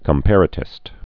(kəm-părə-tĭst)